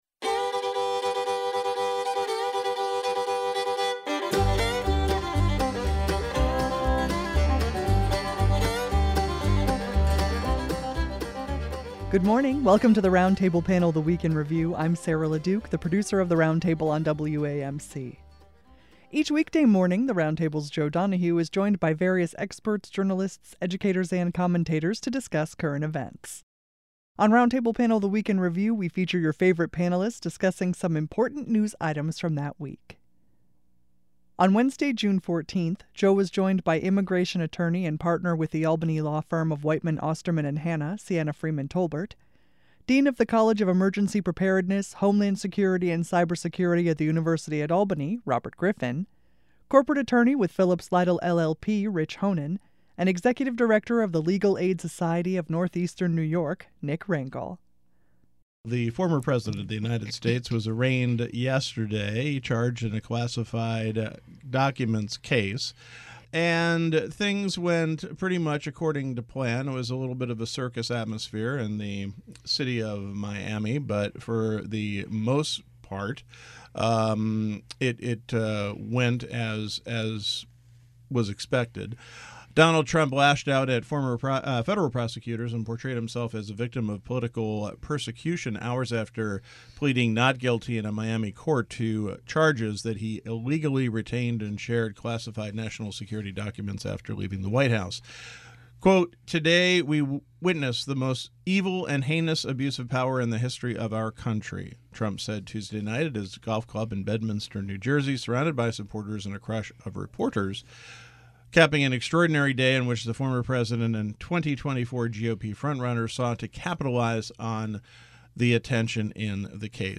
On Roundtable Panel: The Week in Review, we feature your favorite panelists discussing news items from the previous week.